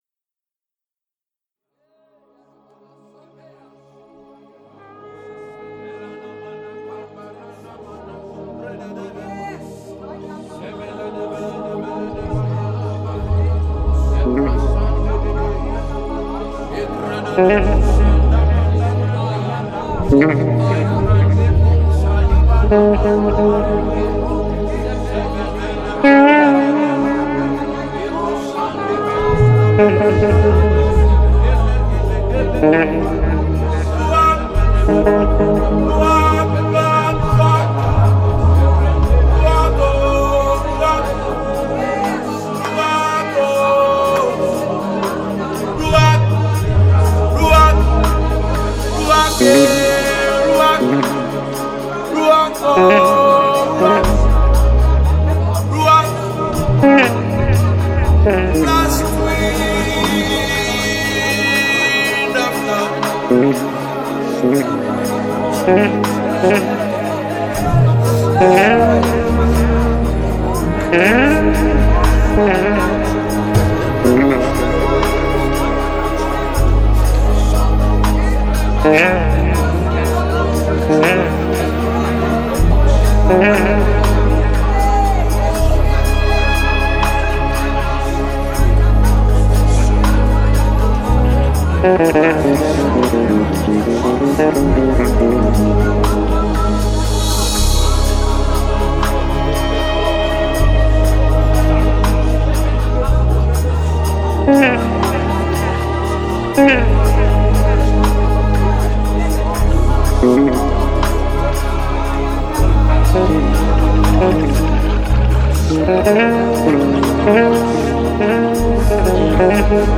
a beautiful melodic sound